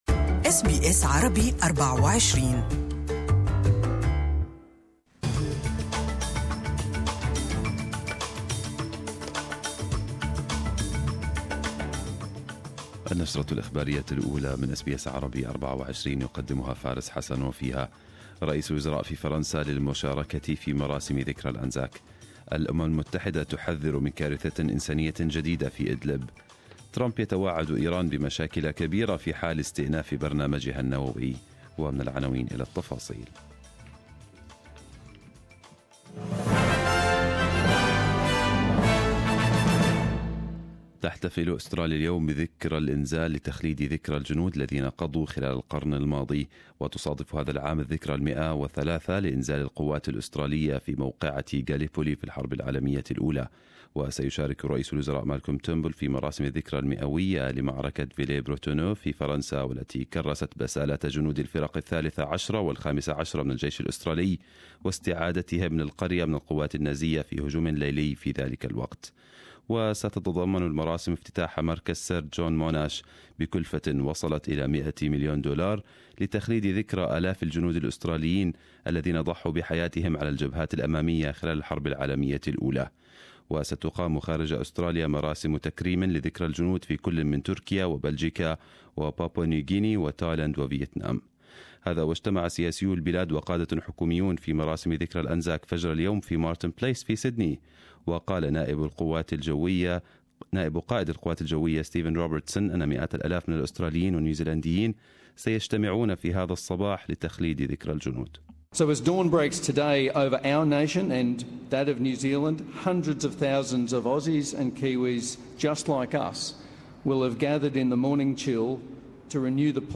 Arabic News Bulletin 25/04/2018